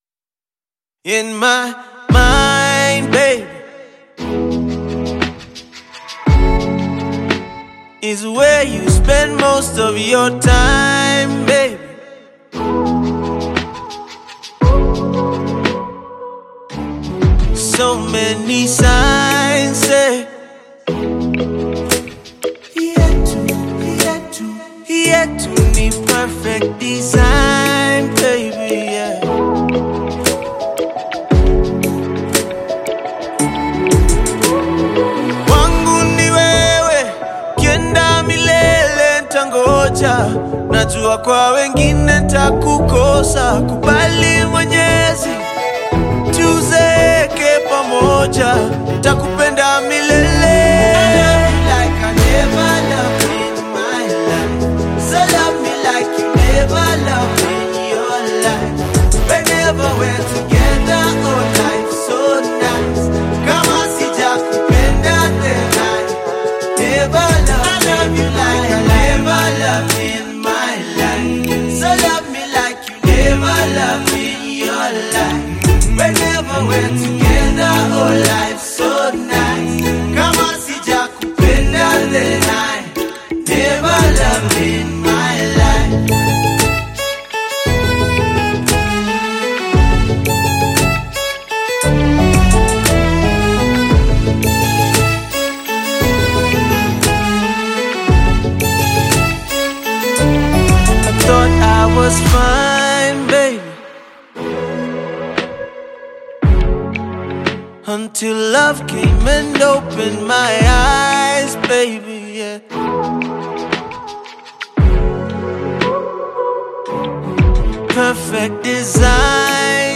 demonstrating his command of R&B and Hip-Hop once more.